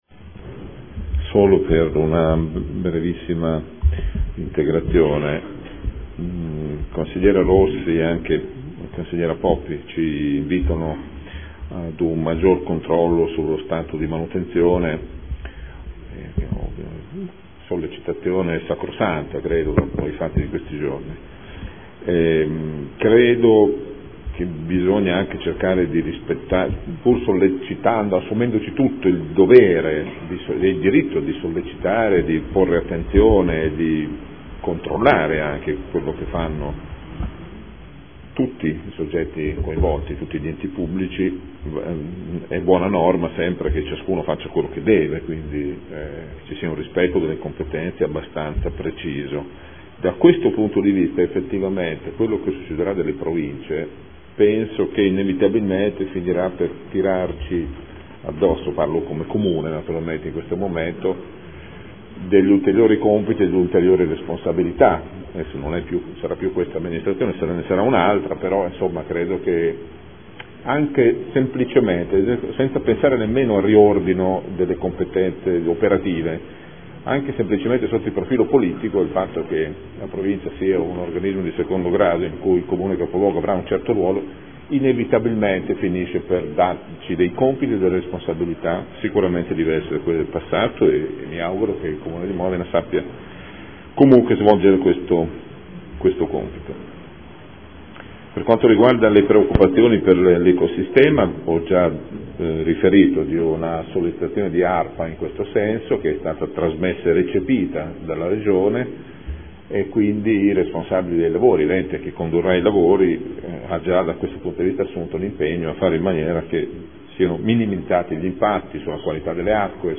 Gabriele Giacobazzi — Sito Audio Consiglio Comunale
Seduta del 23/01/2014 Conclusioni. Variante al Piano Operativo Comunale (POC) – Interventi di messa in sicurezza idraulica della città di Modena – Canale diversivo Martiniana – Nuova rotatoria sulla S.S. n. 12 “Abetone – Brennero” in località Passo dell’Uccellino